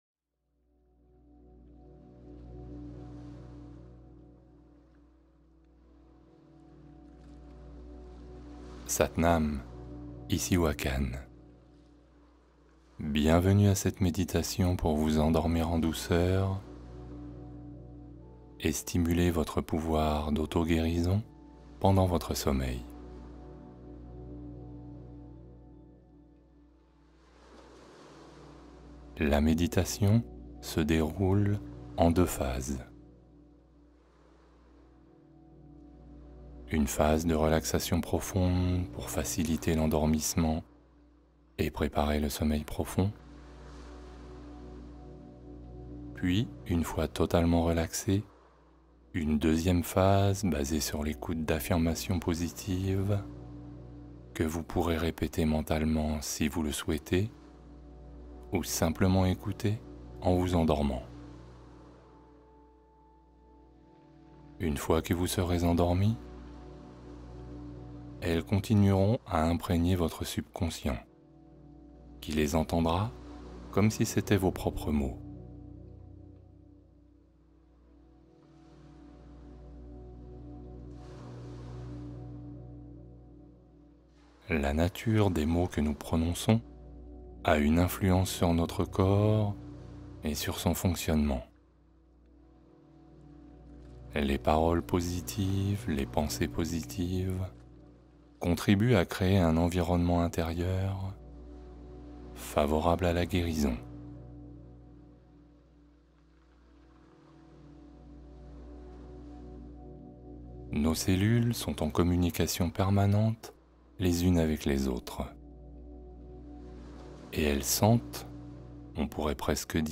Autoguérison pendant le sommeil : méditation réparatrice